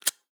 zippo_strike_fail_03.wav